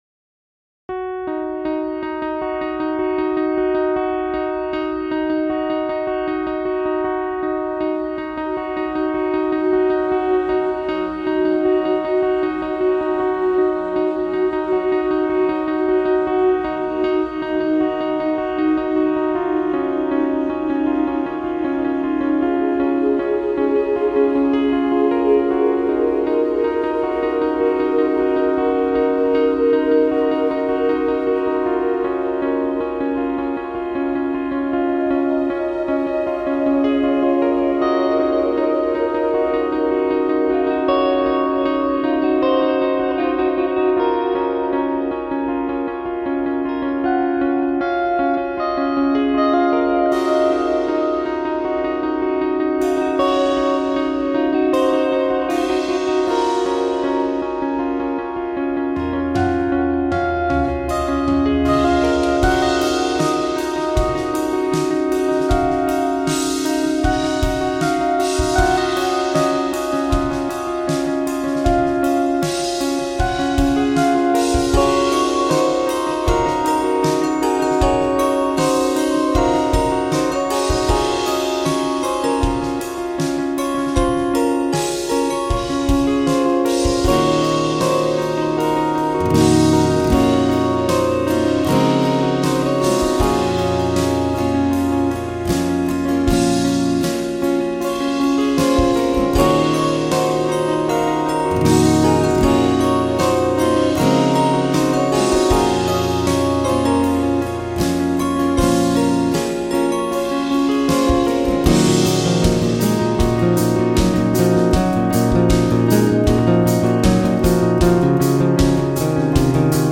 a bit of a mix between organic and elements of fantasy